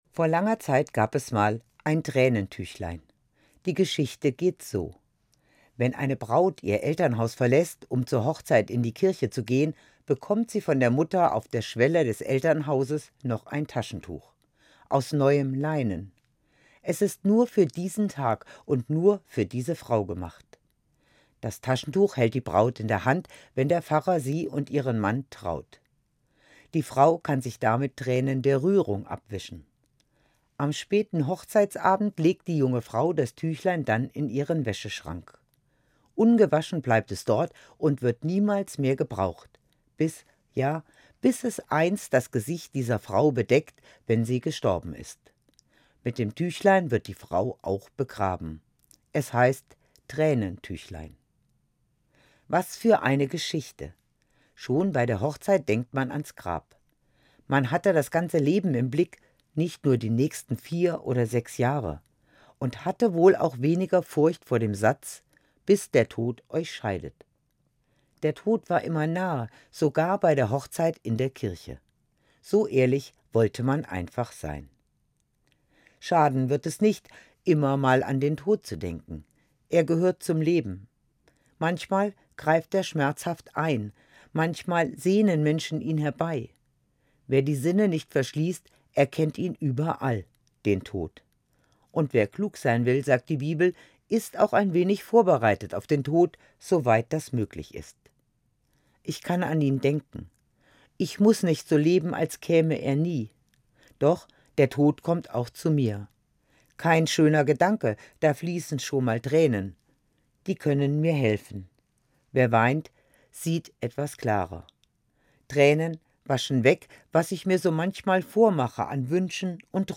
Gesprochen von Pfarrerin